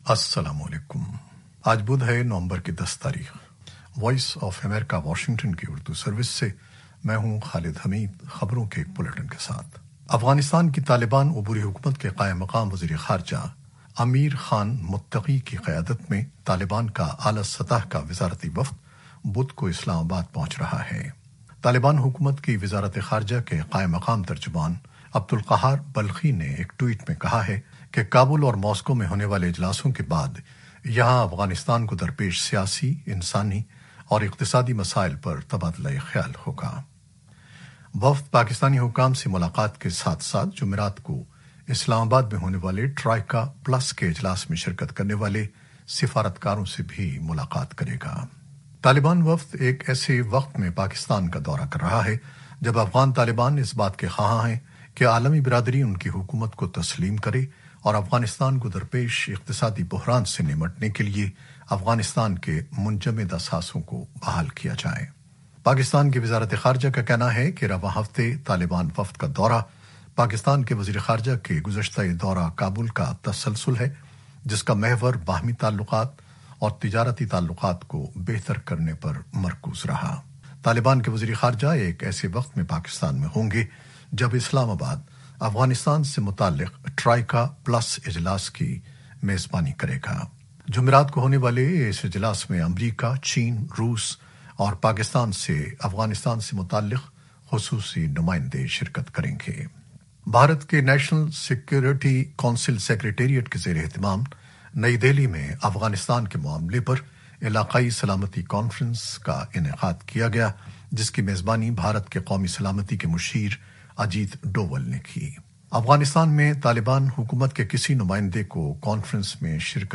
نیوز بلیٹن 2021-10-11